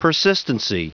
Prononciation du mot persistency en anglais (fichier audio)
Prononciation du mot : persistency